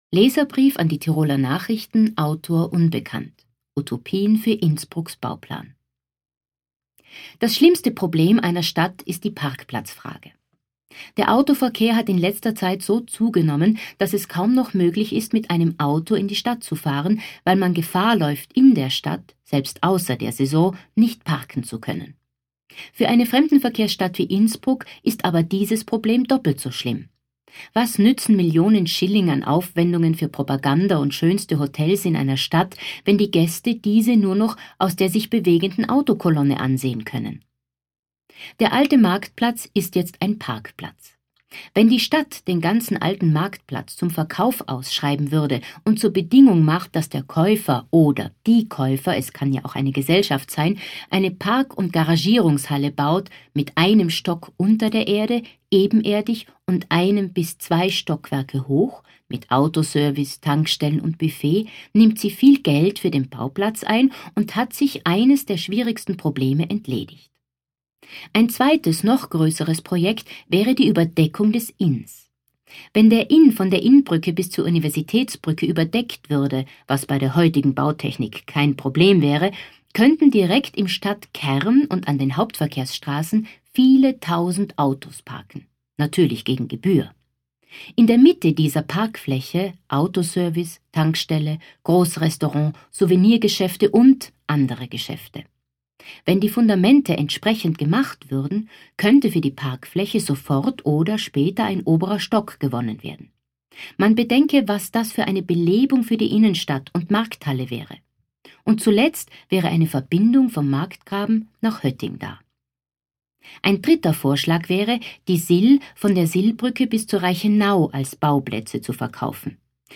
Das anlässlich der Eröffnung von aut im Adambräu erscheinende Lesebuch „reprint“ war die Basis für 11 Hörstationen in der 2005 gezeigten Eröffnungsausstellung vermessungen.